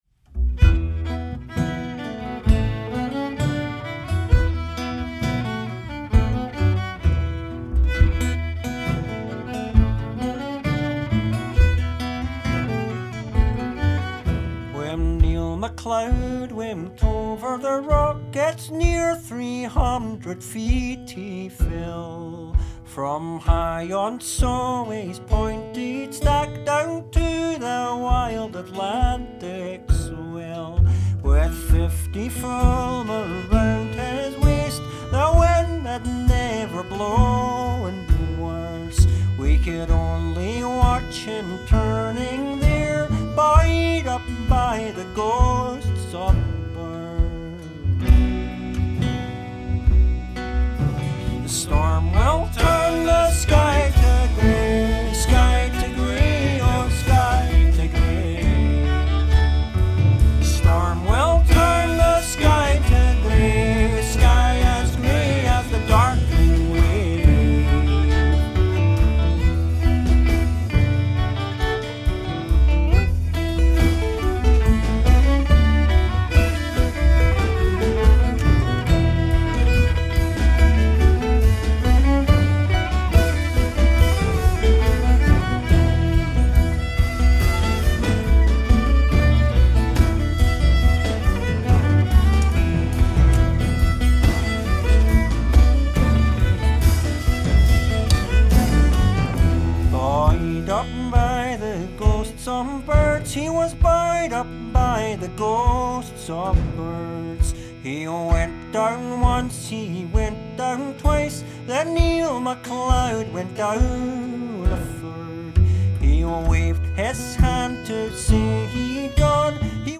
傑作トラッド！
リリシズムを多分に含んだ崇高な歌が絶品！
トラッドの持っているリリシズムを多分に含んだ崇高なメロディを朴訥と歌い上げています！
素朴なメロディを引き立てる美しい演奏を聴かせてくれますよ！